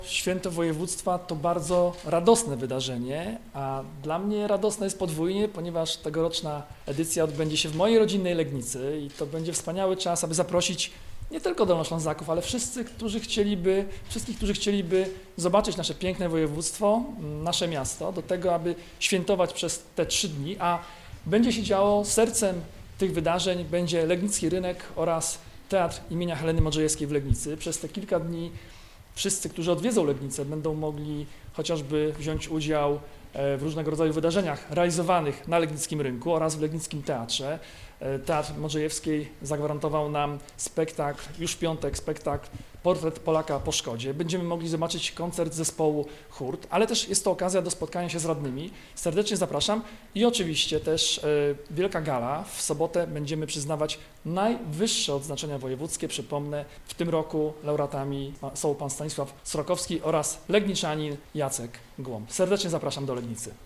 Jarosław Rabczenko – Członek Zarządu Województwa nie kryje radości z faktu, że tegoroczna edycja odbędzie się w jego rodzinnym mieście.